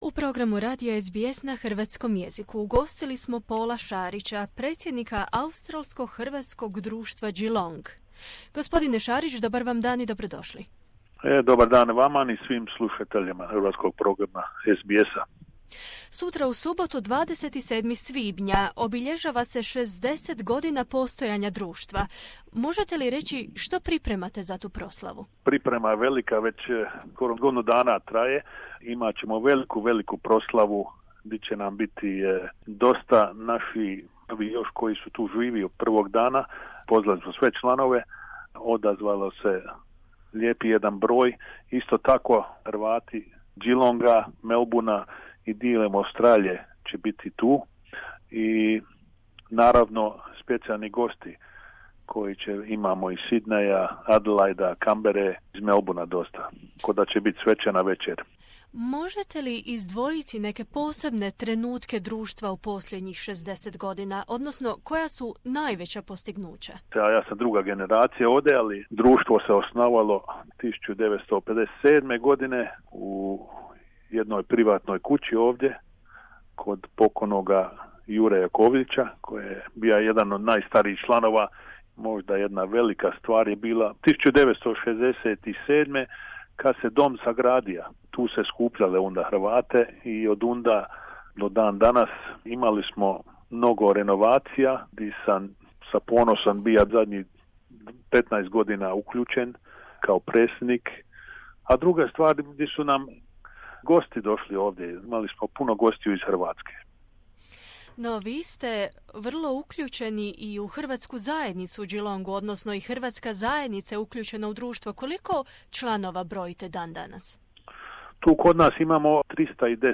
u razgovoru za Radio SBS